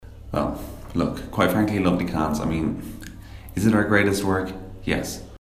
Tags: interview